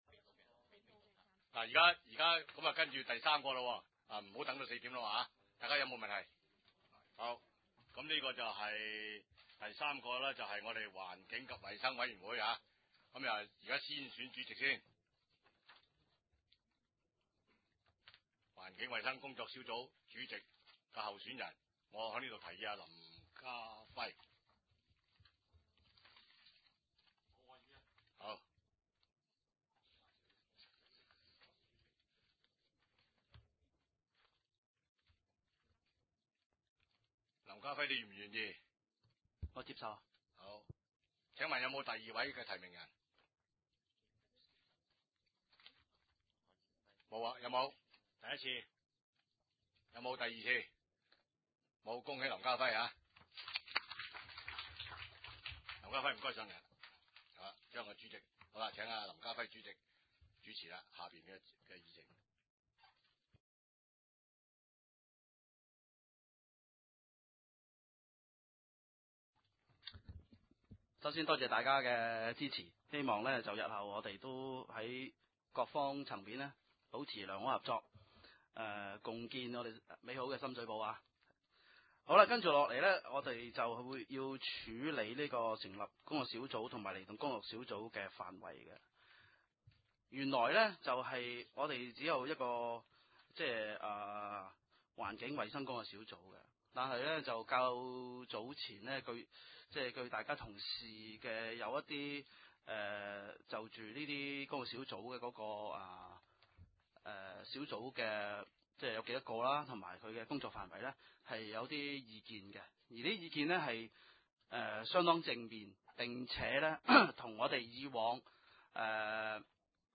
二零零八年一月十一日 第三屆深水埗區議會 環境及衞生委員會第一次會議議程 日期： 二零零八年一月十一日 ( 星期五 ) 時間： 下午四時至 四時四十五分 地點： 九龍長沙灣道 303 號長沙灣政府合署 4 字樓 深水埗區議會會議室 議 程 討論時間 1. 選舉委員會主席 2. 成立工作小組及釐定工作小組職權範圍 ( 環境及衞生委員會文件 1/08 ） 3.